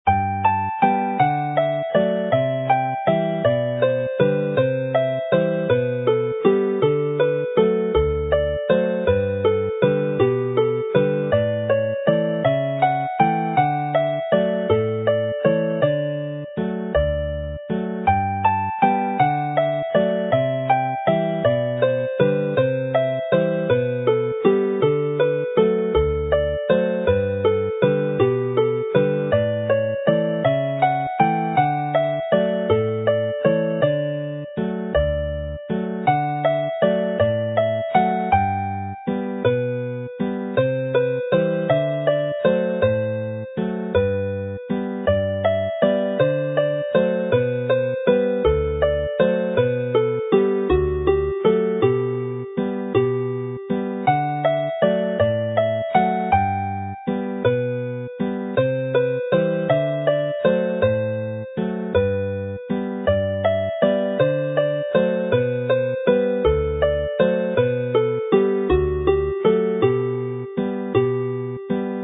The set concludes with another lively jig
Play the melody slowly